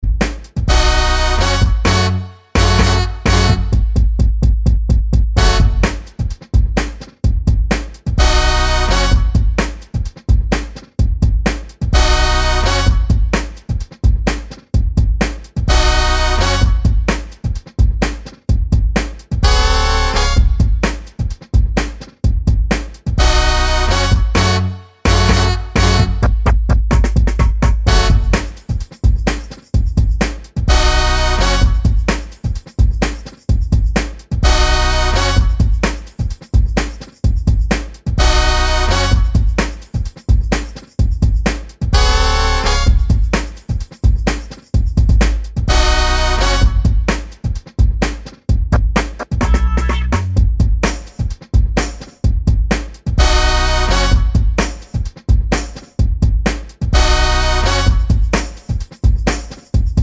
no Backing Vocals Pop (2010s) 3:37 Buy £1.50